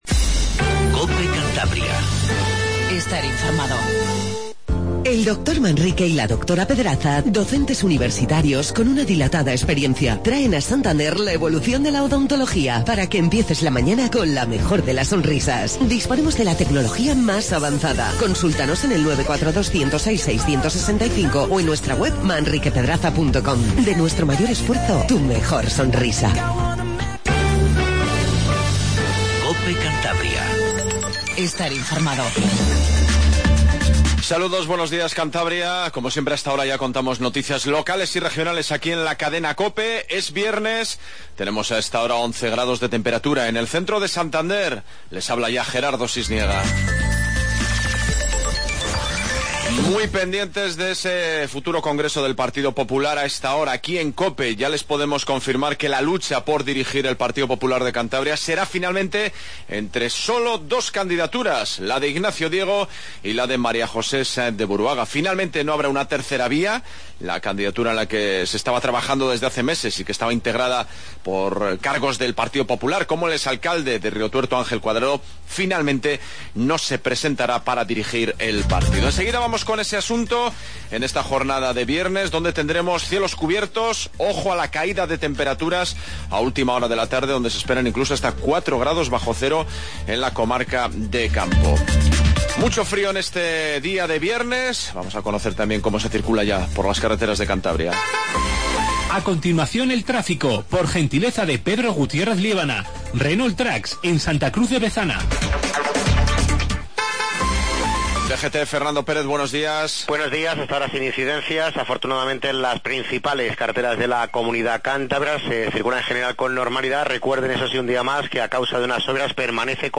INFORMATIVO MATINAL 07:50